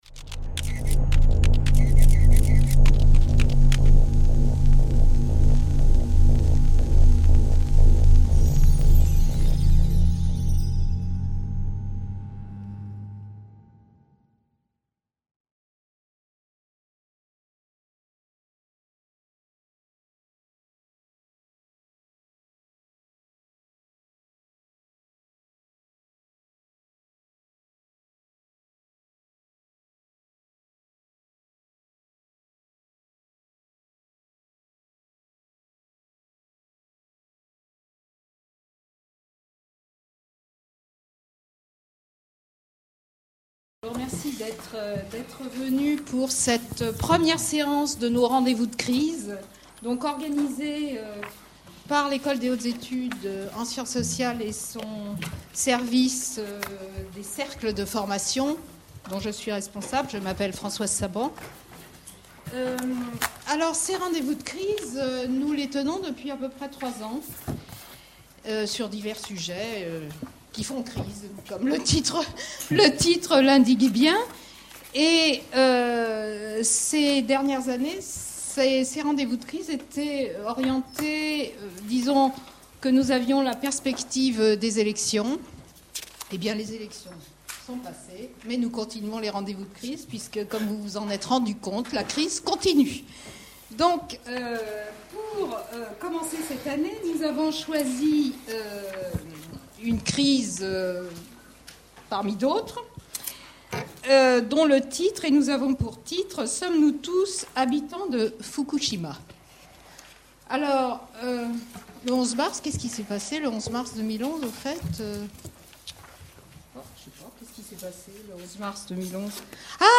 Débat dans le cadre du cycle Rendez-vous de crise organisé par les Cercles de formation de l'EHESS.